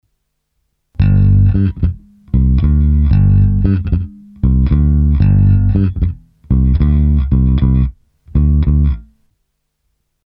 Nejdřív jen stejné kolečko samotného tracku basy:
Fender Jazz Bass